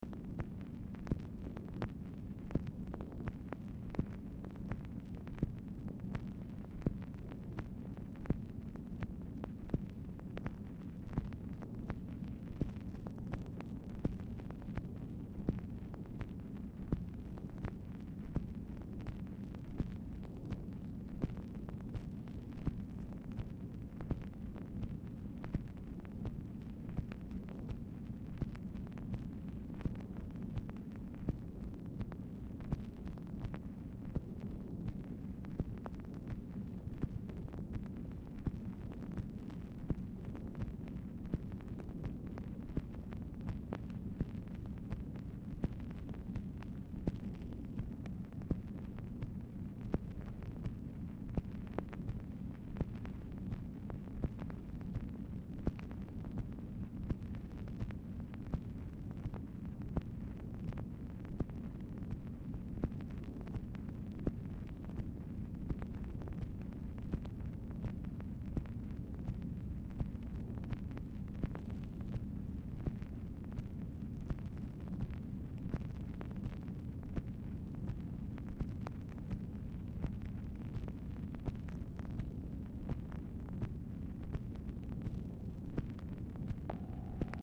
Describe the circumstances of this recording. Format Dictation belt